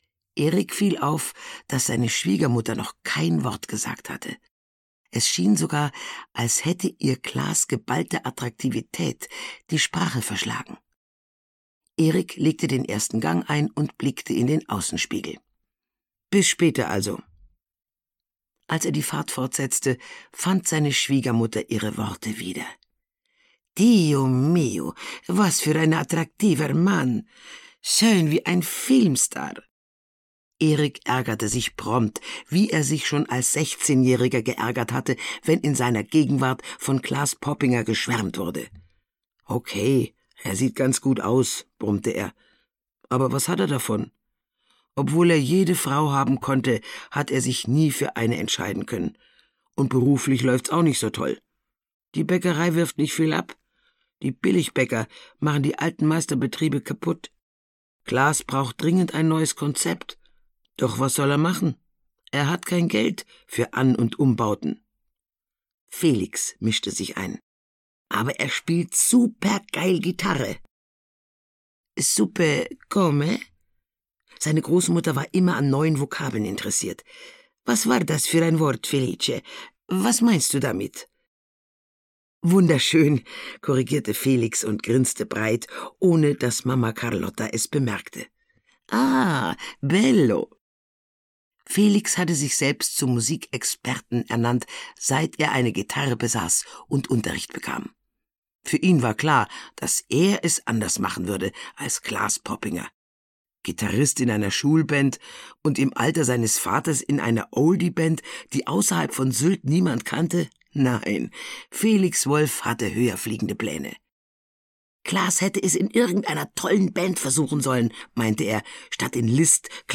Sonnendeck (Mamma Carlotta 9) - Gisa Pauly - Hörbuch